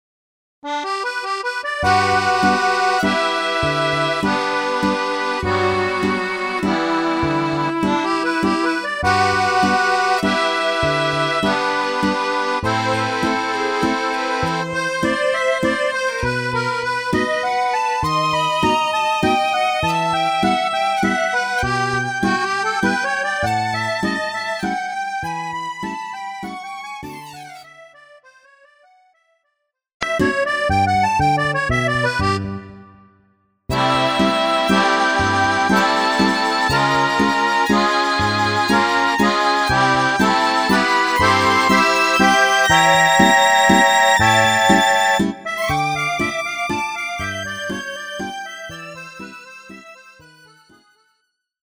PRIX VALSE  LENTE